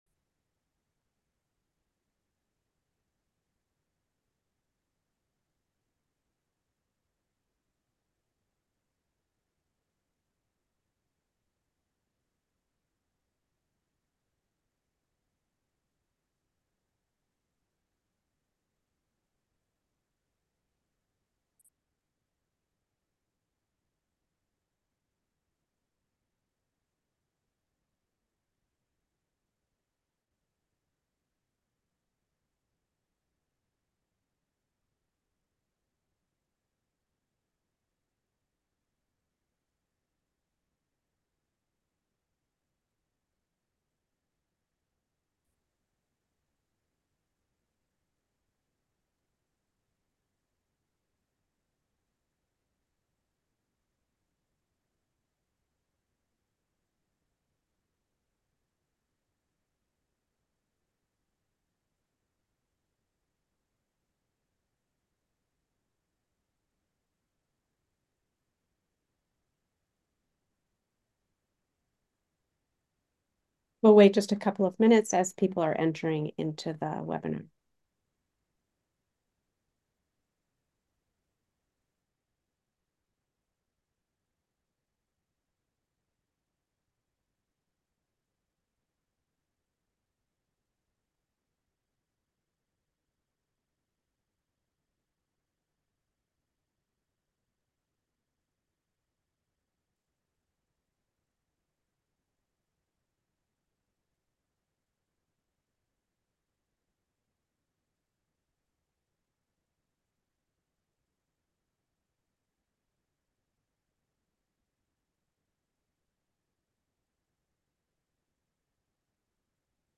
Waterman Awardee Distinguished Lecture